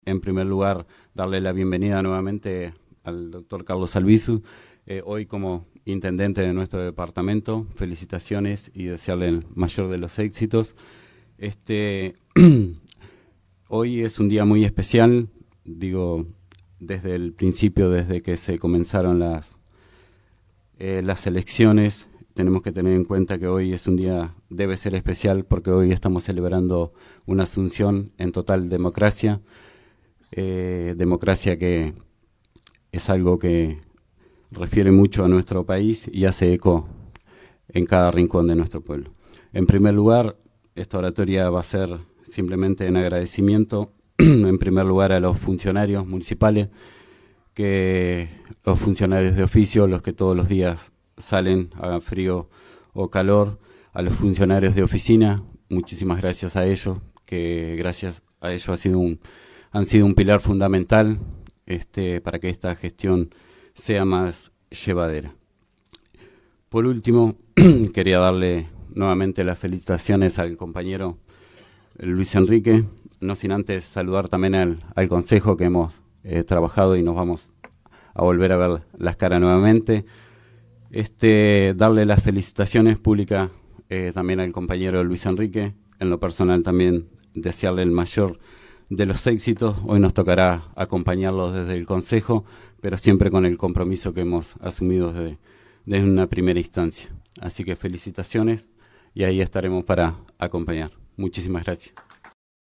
La ceremonia se desarrolló ante un importante marco de público y contó con la presencia de autoridades locales, departamentales y nacionales.
La oratoria fue iniciada por el alcalde saliente, Milton Farinha, quien destacó el valor democrático del acto y expresó su agradecimiento a quienes lo acompañaron durante su gestión.